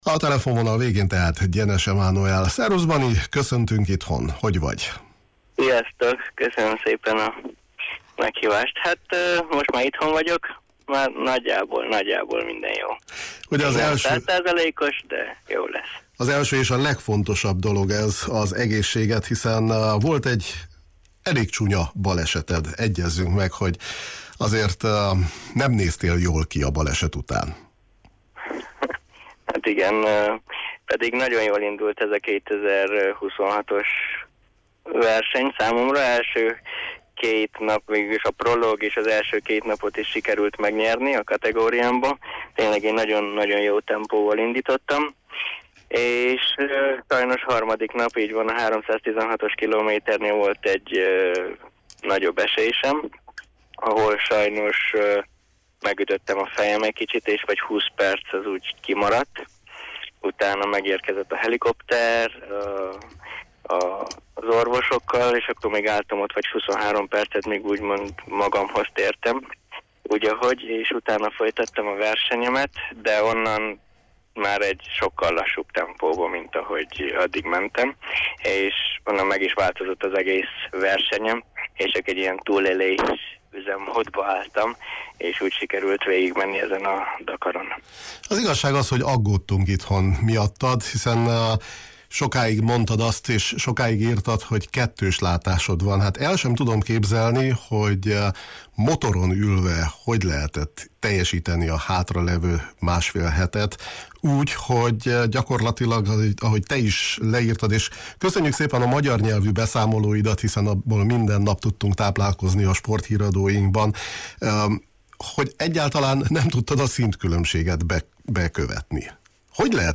beszélgetett a Ksipadon: